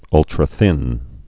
(ŭltrə-thĭn)